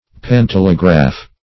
Pantelegraph \Pan*tel"e*graph\, n. [Pan- + telegraph.]